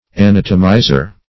Anatomizer \A*nat"o*mi`zer\